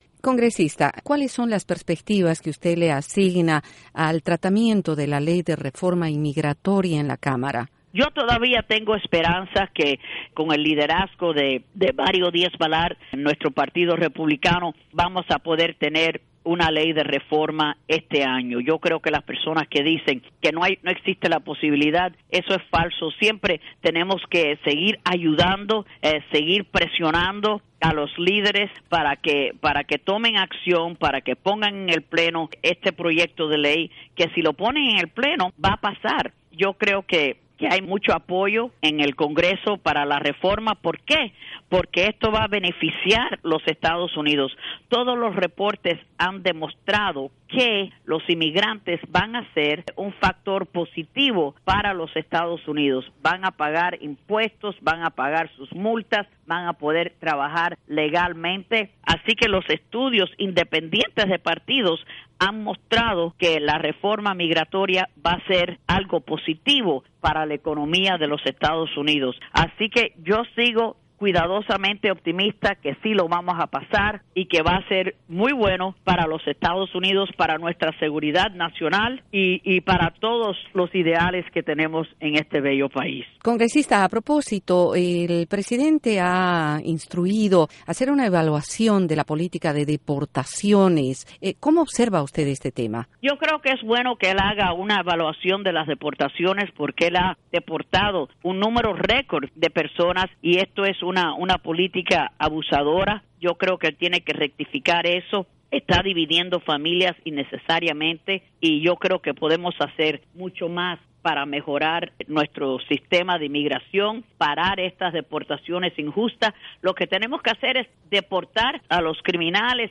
Entrevista a Ileana Ros-Lehtinen - Congresita Republicana